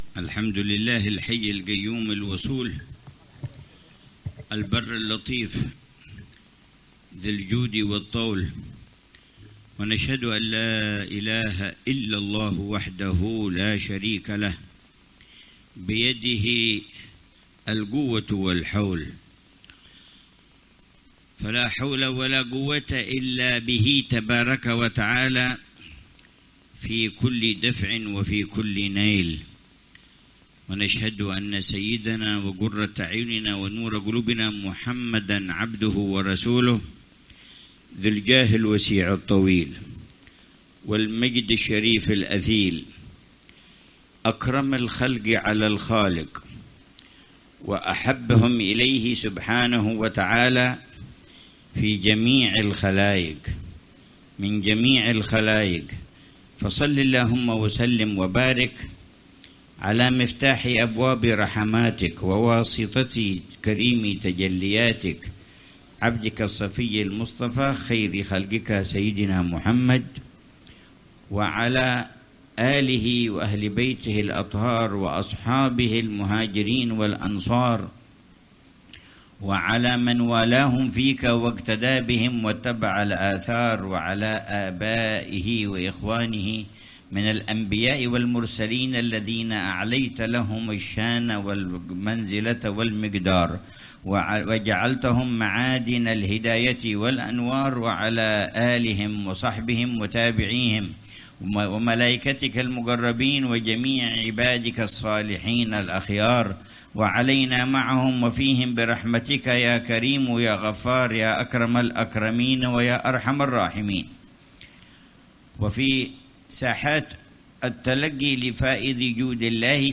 محاضرة في الجلسة السنوية في ذكرى الإمام المهاجر إلى الله أحمد بن عيسى، في دار الصفا، بحارة الرحبة، مدينة تريم، ليلة الأحد 18 محرم 1447هـ بعنوان: